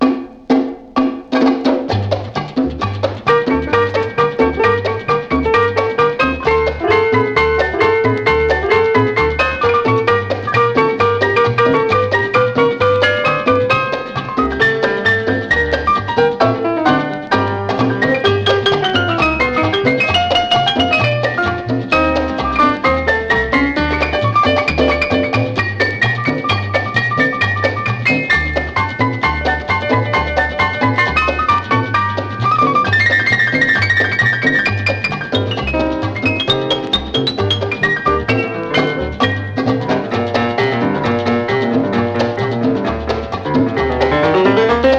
World, Latin, Jazz　USA　12inchレコード　33rpm　Mono
盤擦れキズ　プチプチノイズ